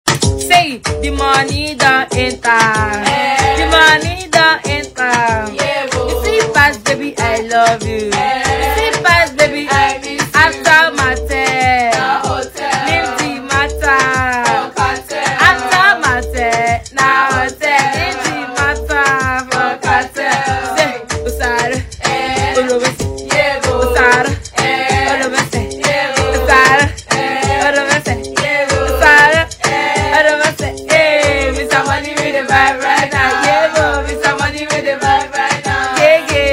freestyle
Nigerian female star vocalist